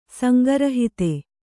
♪ sanga rahite